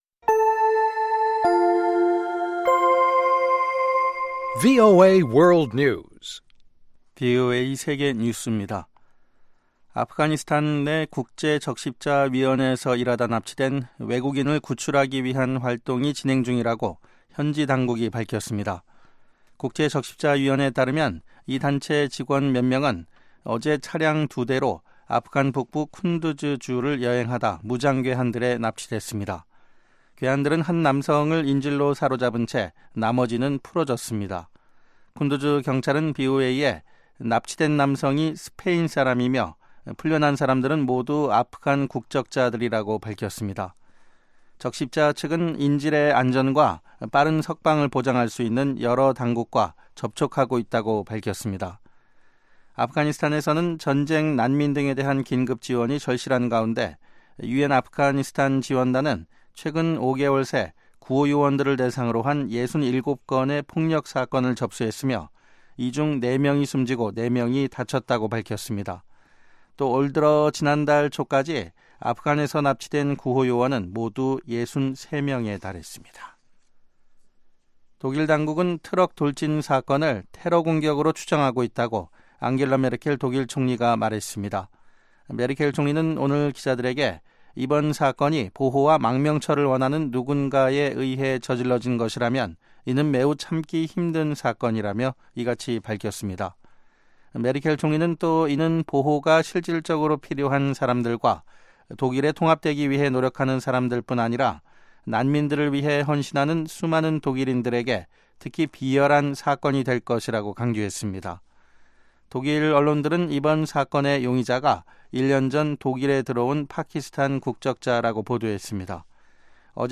VOA 한국어 방송의 간판 뉴스 프로그램 '뉴스 투데이' 3부입니다.